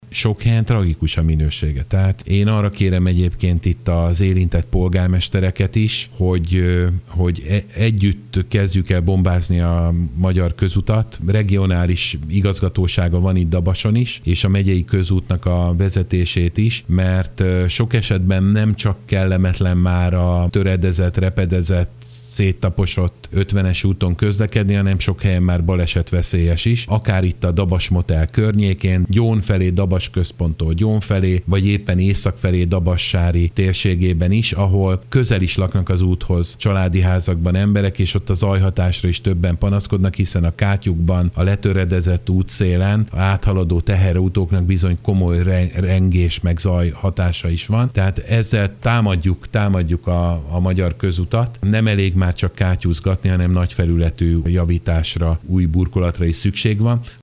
Hírek